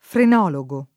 frenologo [ f ren 0 lo g o ] s. m.; pl. -gi